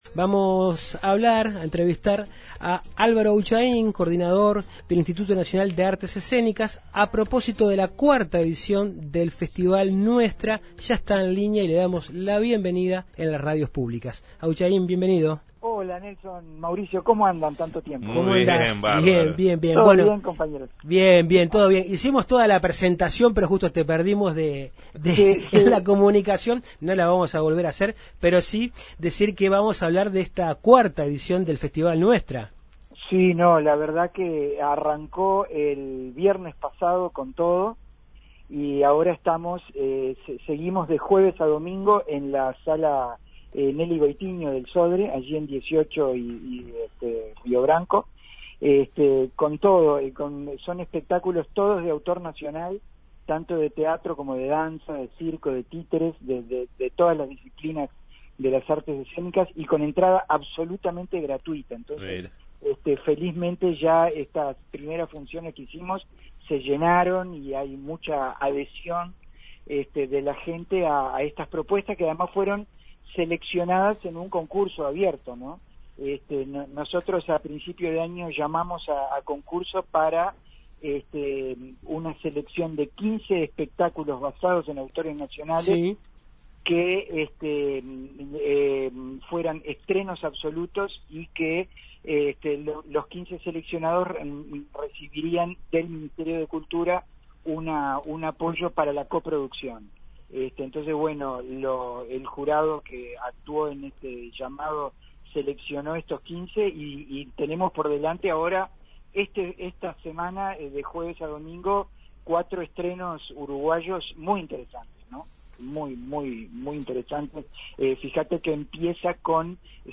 Entrevista al coordinador del INAE, Álvaro Ahunchain
En Justos y pecadores dialogamos con Álvaro Ahunchain, coordinador del Instituto Nacional de Artes Escénicas (INAE).